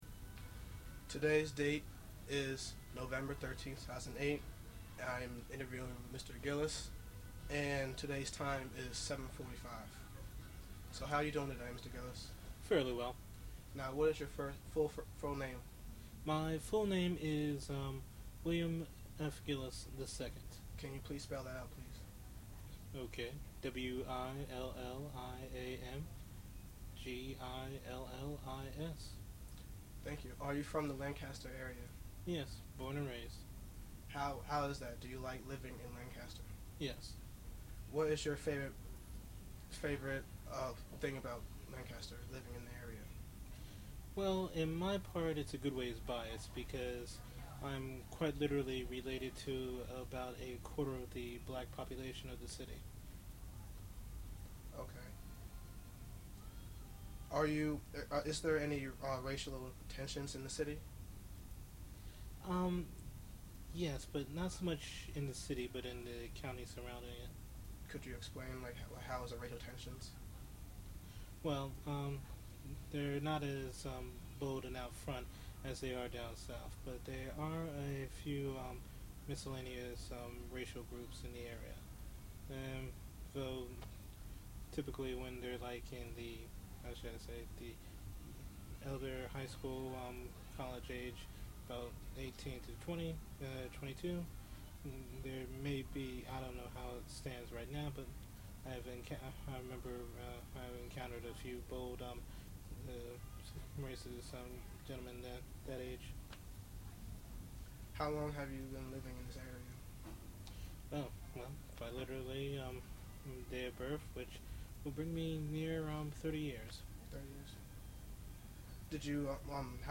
Oral histories., lcgft